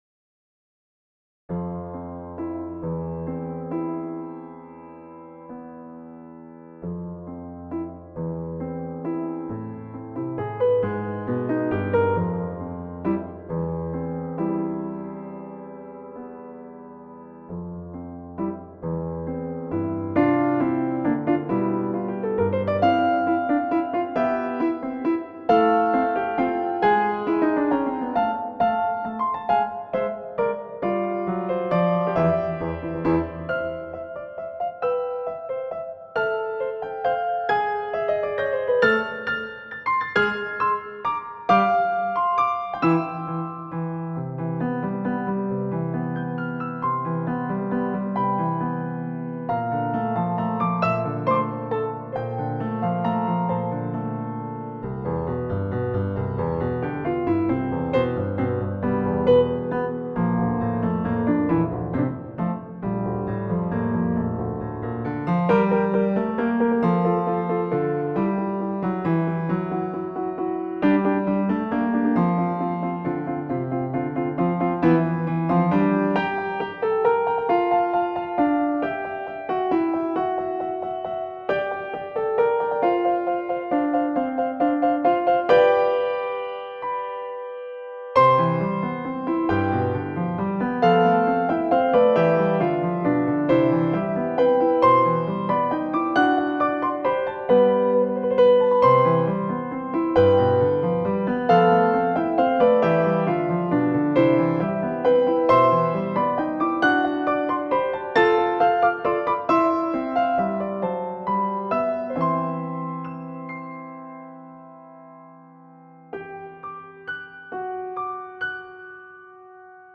Instrumental track